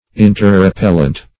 interrepellent - definition of interrepellent - synonyms, pronunciation, spelling from Free Dictionary
Interrepellent \In`ter*re*pel"lent\